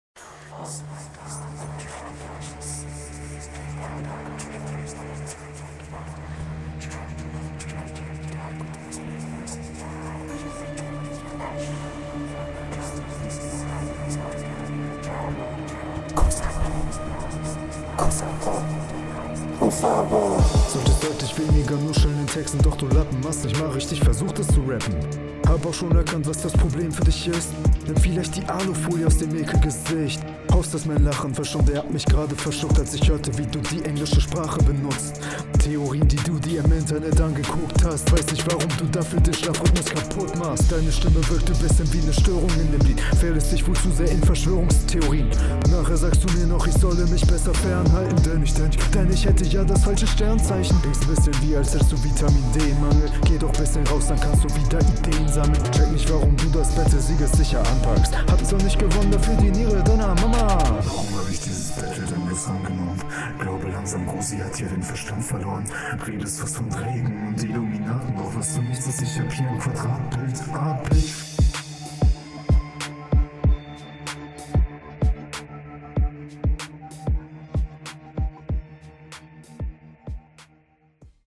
Die mische ist etwas ass aber sonnst geil ding ^-^